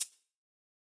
ORG Shaker.wav